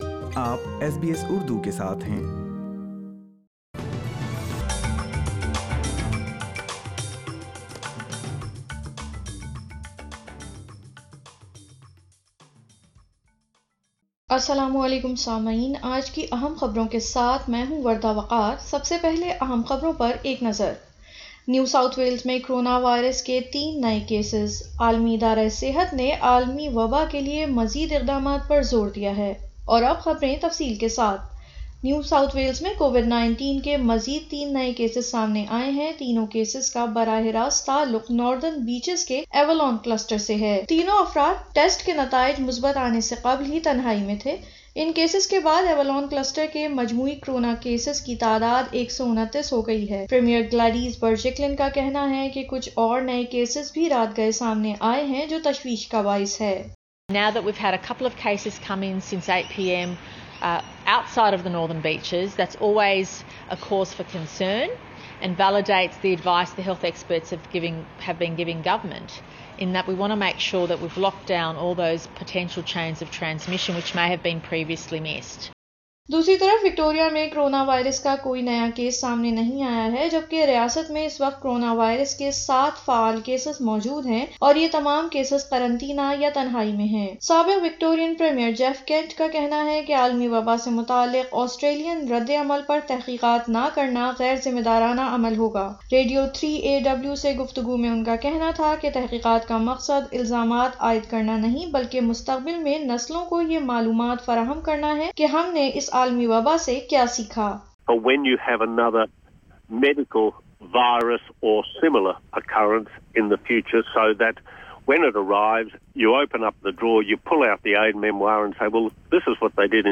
اردو خبریں 29 دسمبر 2020
نیو ساوتھ ویلز میں کرونا وائرس کے مزید کیسسز، عالمی ادارہ صحت کی جانب سے عالمی وبا کے خاتمی کے لئے پرعزم اقدامات پر زور ۔ سنئیے اردو میں خبریں ۔